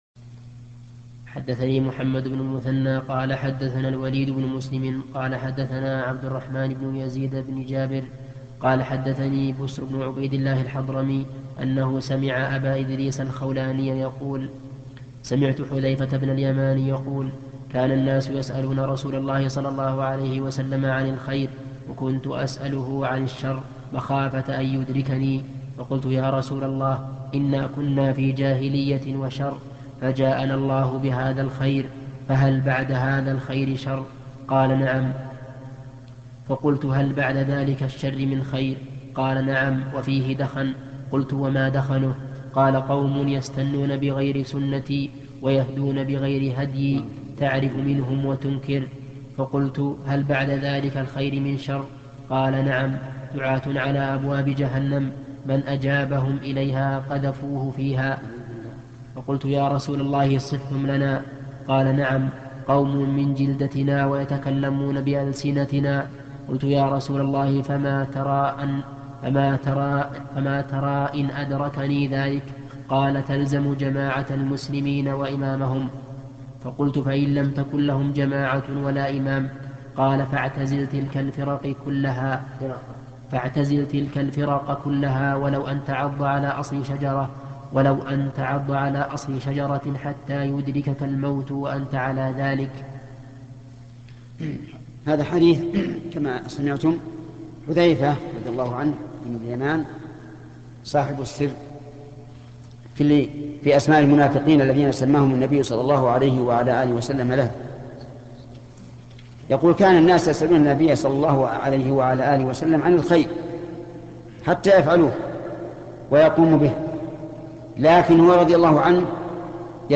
شرح حديث حذيفة : تلزم جماعة المسلمين و إمامهم - الشيخ بن عثيمين